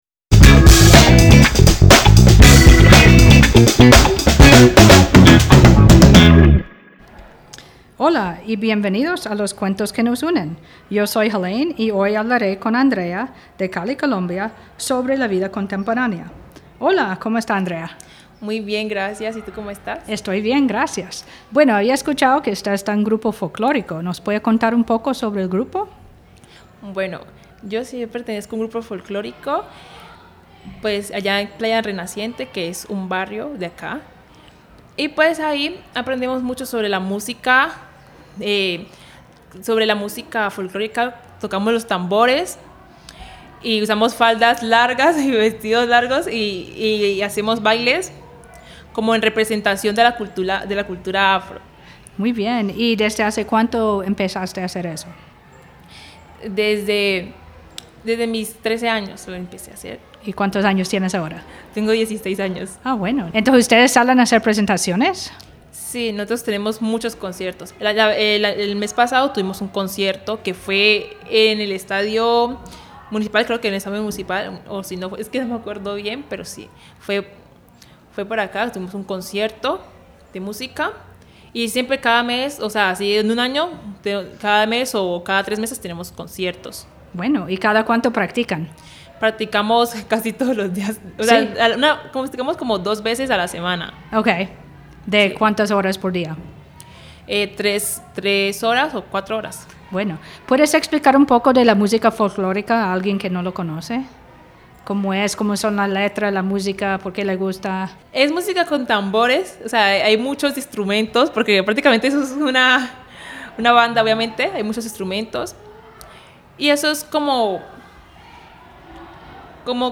Al final del podcast, canta dos de sus canciones.